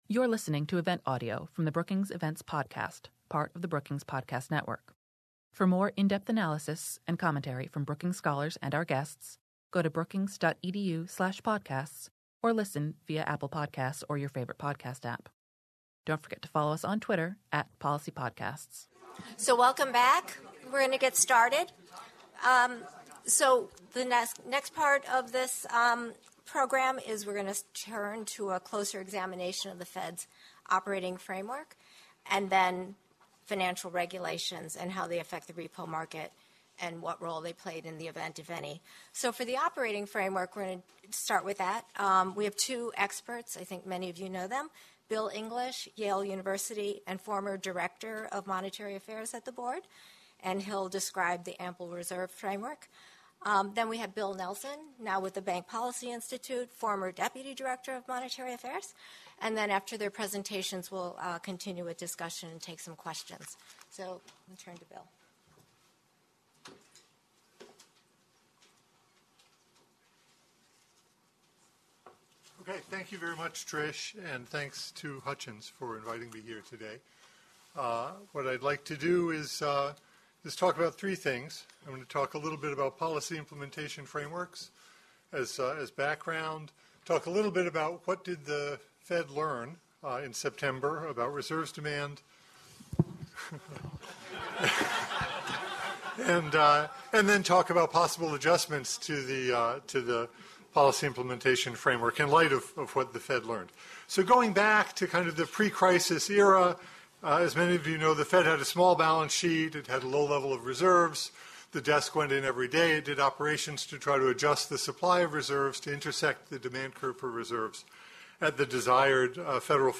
On Thursday, December 5, the Hutchins Center on Fiscal and Monetary Policy at Brookings hosted an event to discuss what happened in the repo markets in September and why — and what, if anything, the Fed or other regulators should do about it.